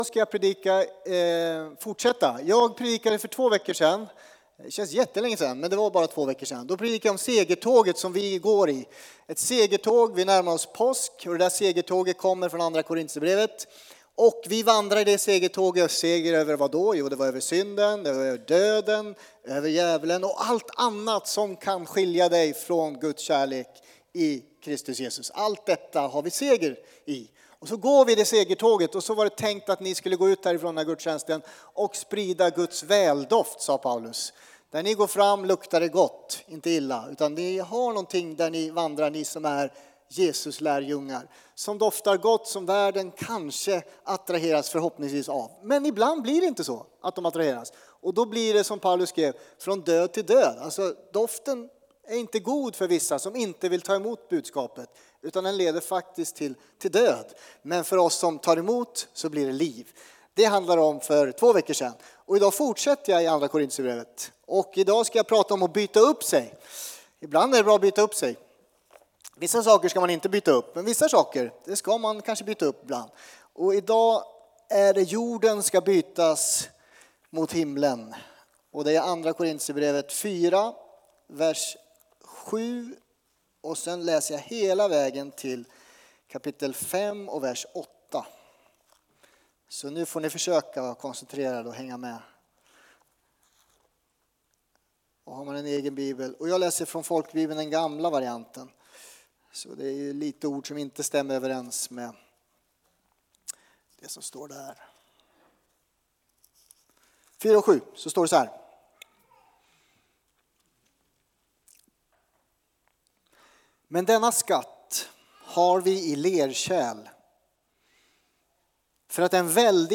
Predikan 4:e april